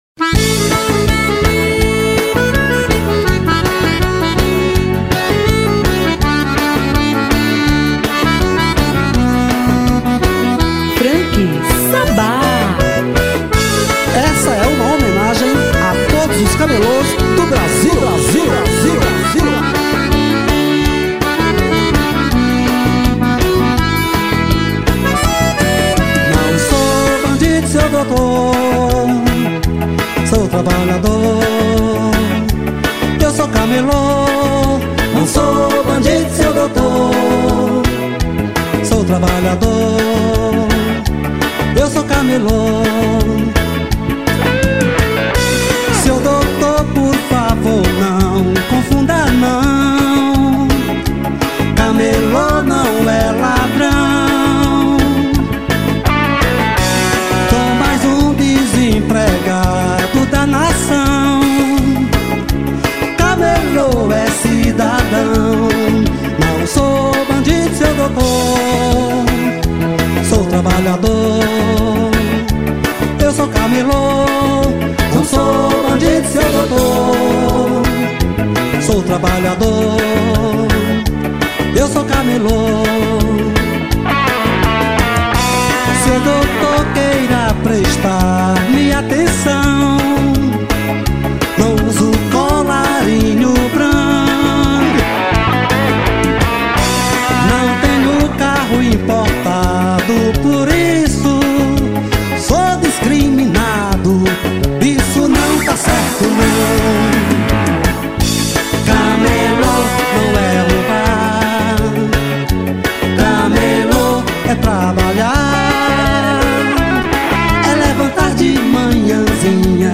EstiloBrega